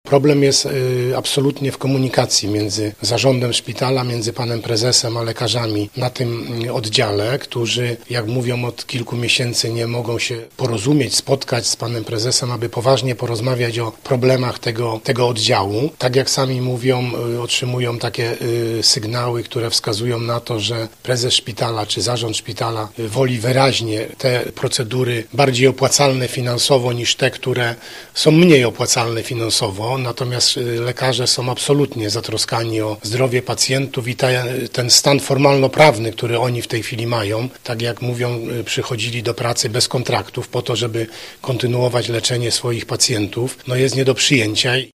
Mówi Władysław Dajczak: